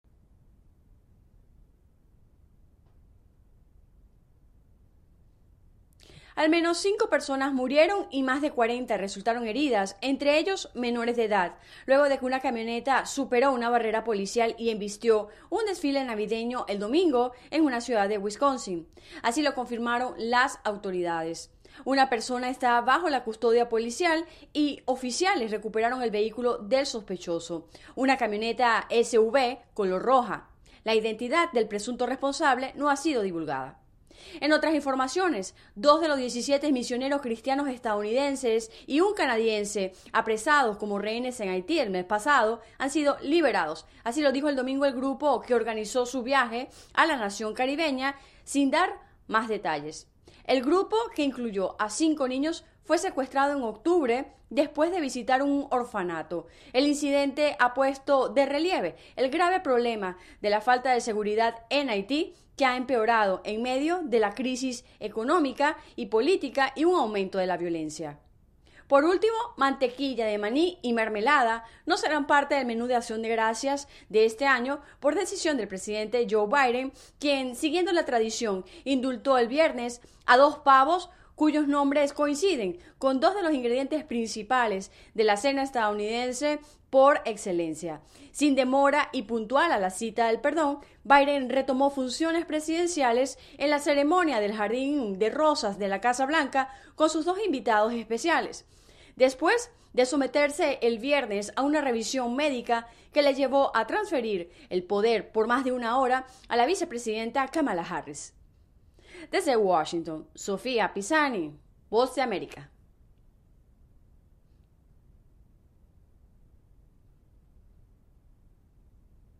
La agenda del día [Radio]